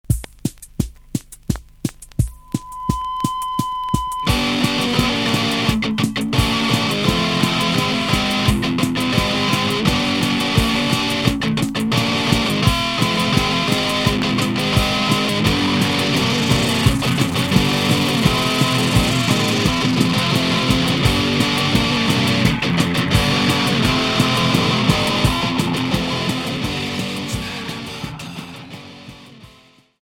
Punk industriel